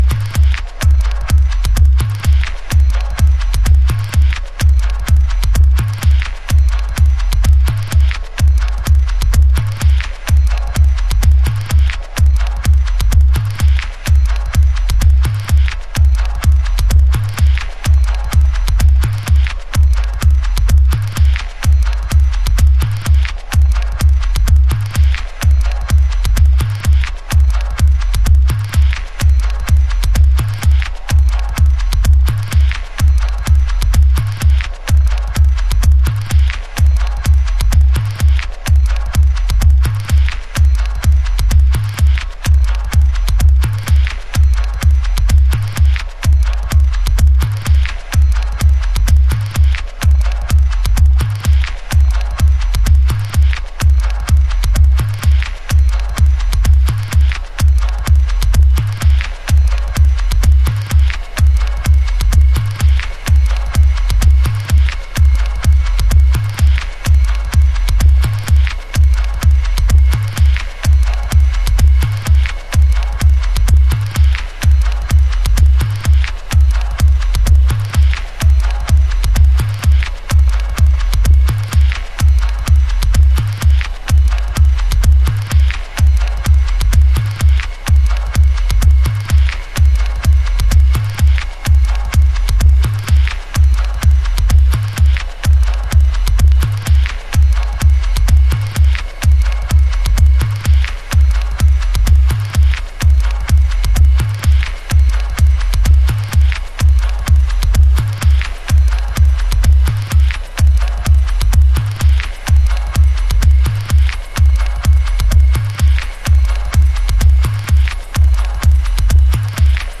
House / Techno
低域でうねるソフトなトランス感とアブストラクトな音響がマッチしたミニマルテクノ。